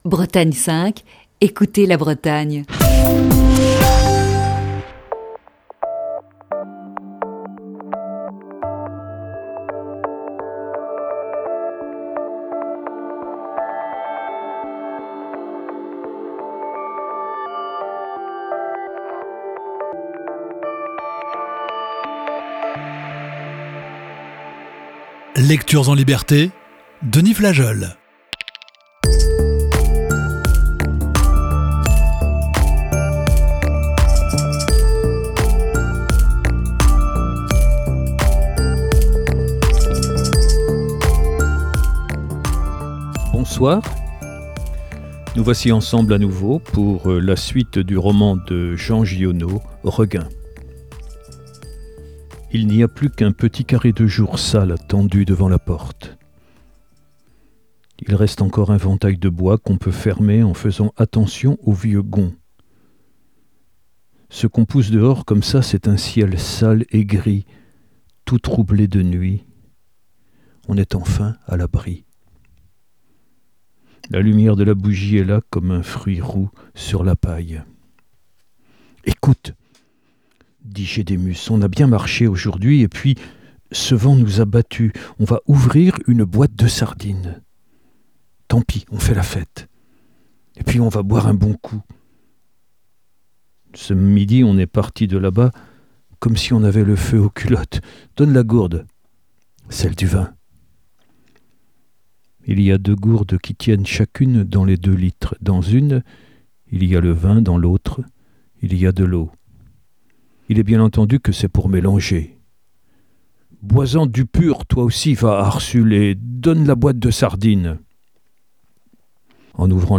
Émission du 10 décembre 2020.